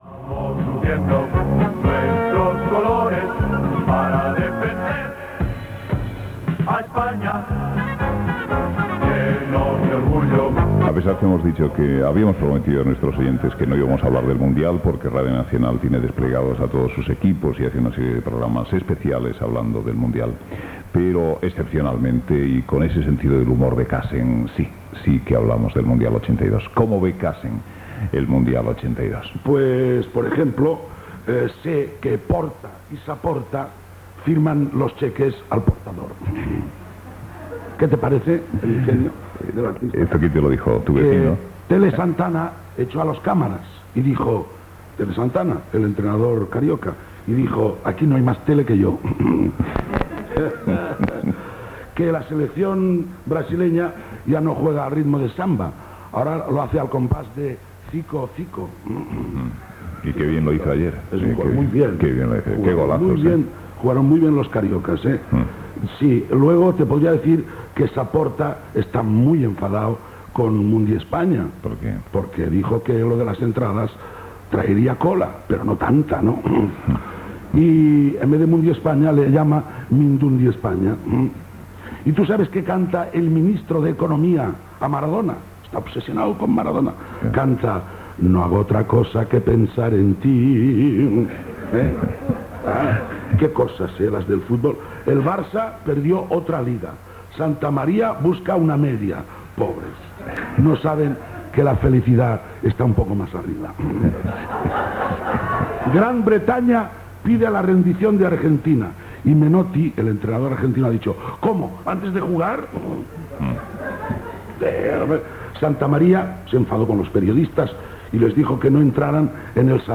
Intervenció de l'humorista Cassen (Cast Sendra) amb acudits deicats al Mundial de Futbol España 1982 i d'altres telefònics ràpids
Info-entreteniment